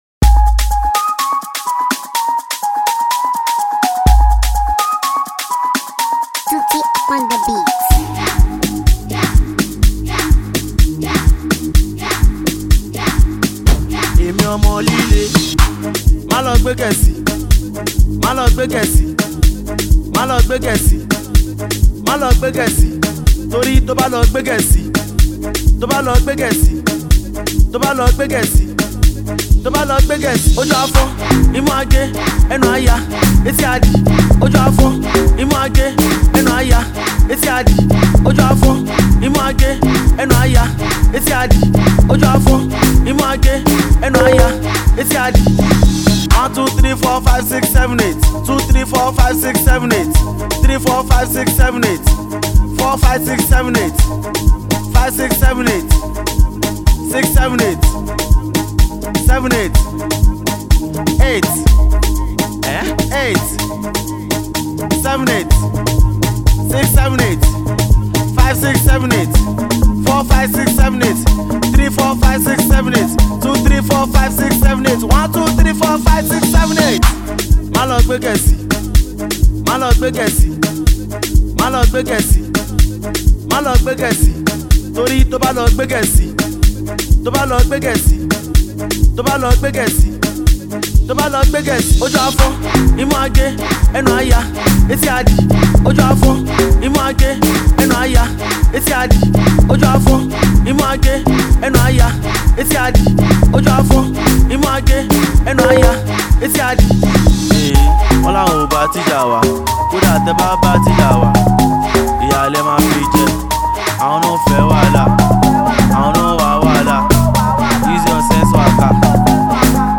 a Nigerian afro singer